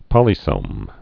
(pŏlē-sōm)